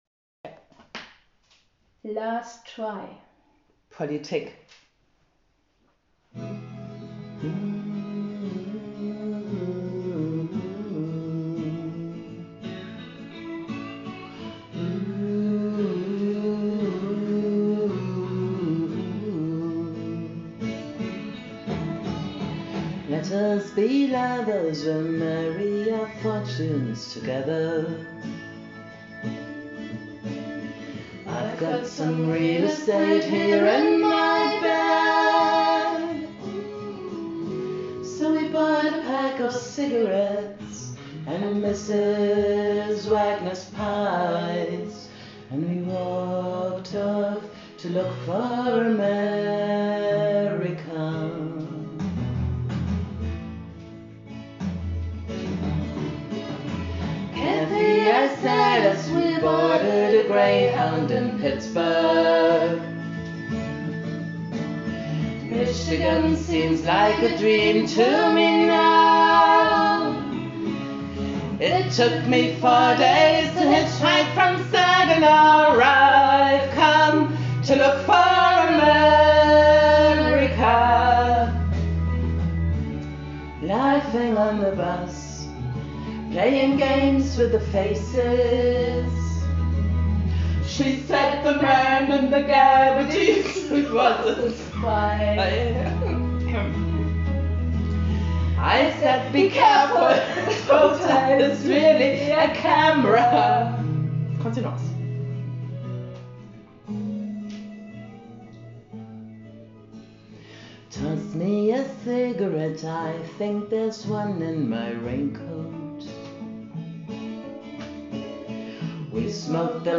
Mit Inbrunst.